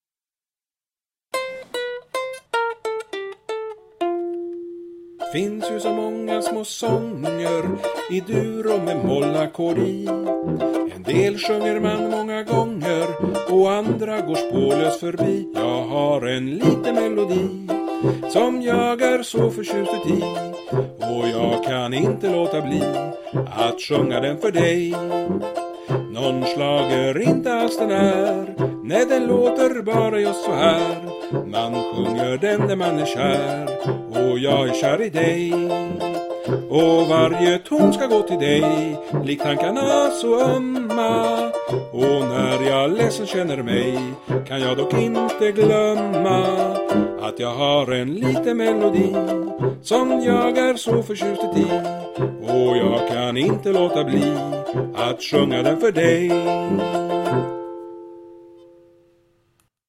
Du sjunger riktigt bra.
�delschlager!